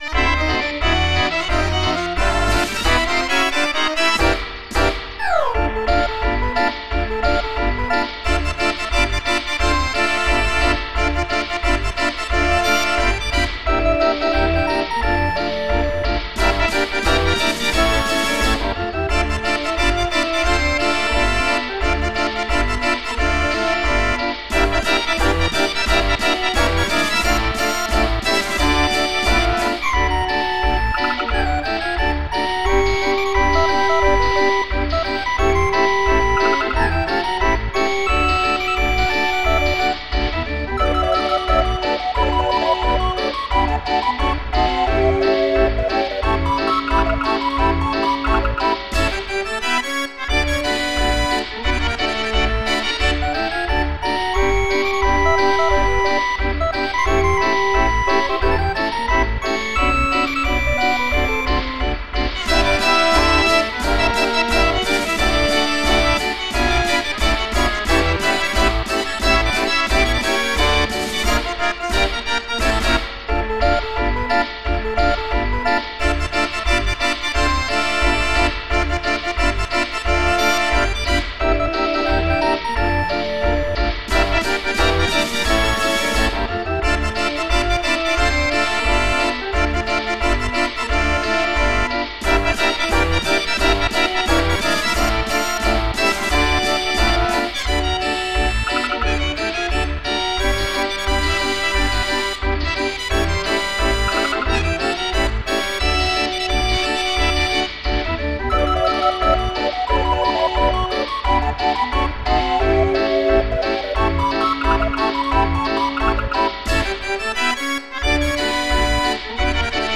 Musikrollen, Notenbücher und Zubehör für Drehorgeln.